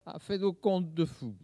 Mots Clé parole, oralité
Catégorie Locution